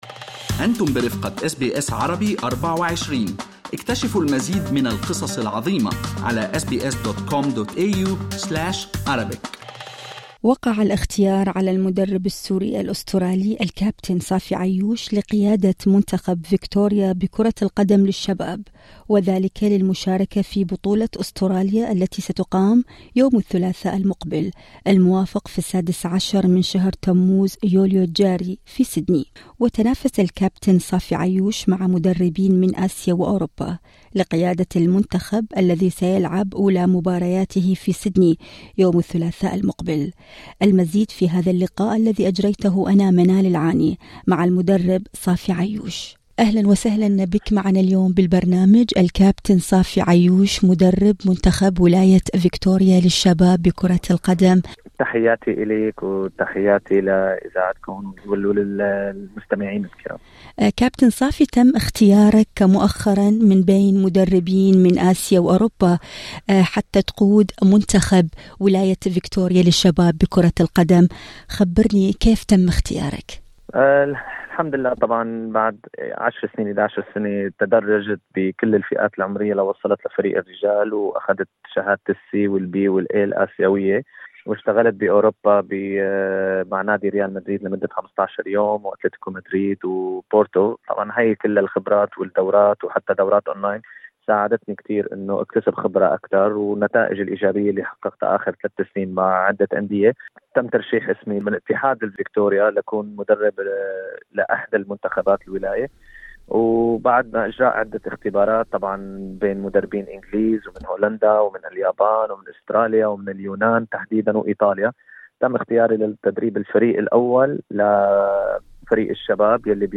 المزيد في المقابلة الصوتية اعلاه استمعوا لبرنامج "أستراليا اليوم" من الاثنين إلى الجمعة من الساعة الثالثة بعد الظهر إلى السادسة مساءً بتوقيت الساحل الشرقي لأستراليا عبر الراديو الرقمي وتطبيق Radio SBS المتاح مجاناً على أبل وأندرويد.